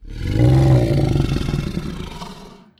Throw_Grunt.wav